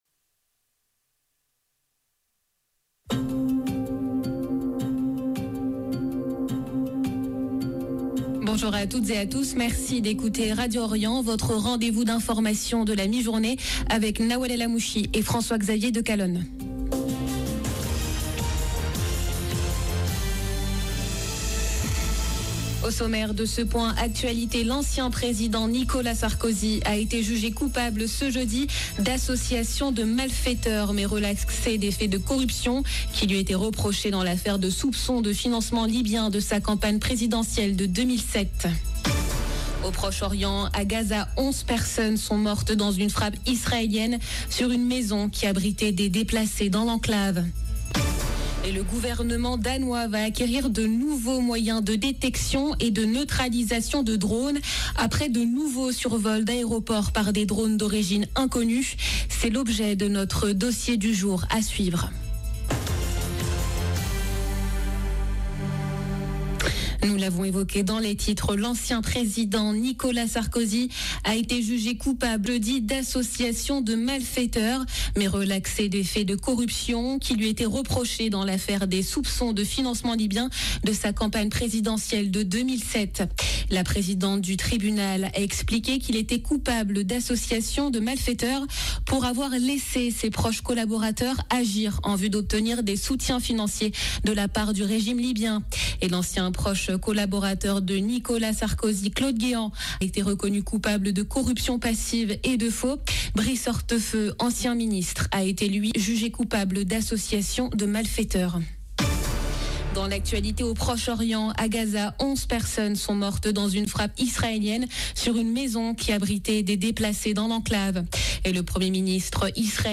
Journal de midi du 25 septembre 2025